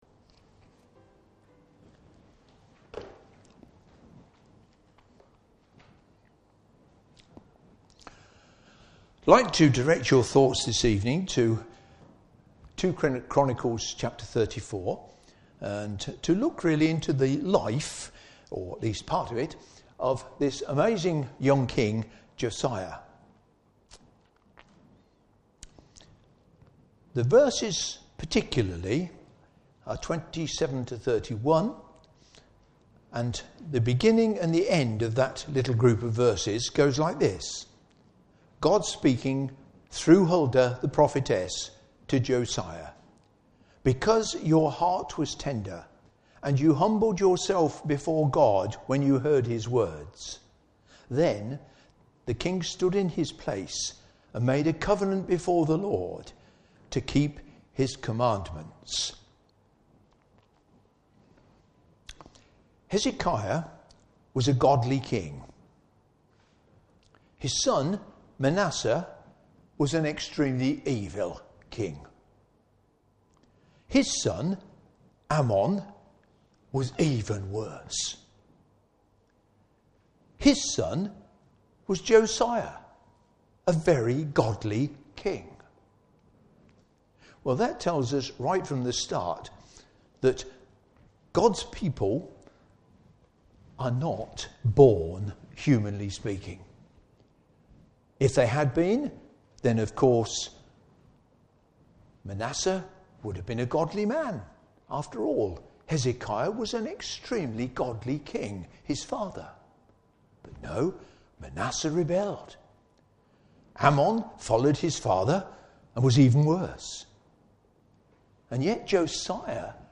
2 Chronicles Service Type: Evening Service Bible Text